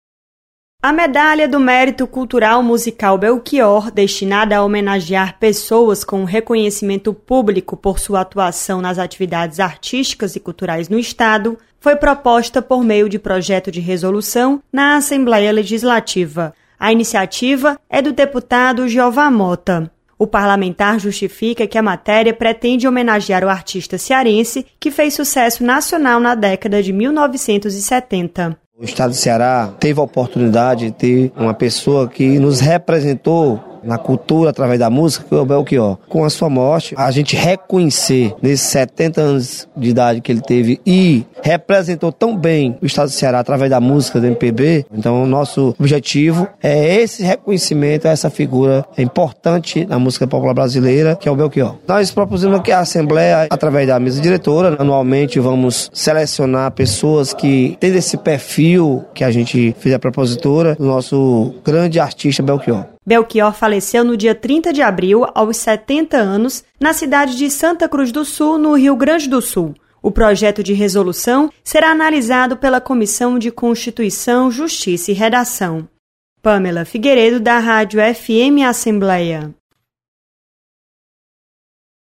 Projeto institui medalha em homenagem ao cantor e compositor Belchior. Repórter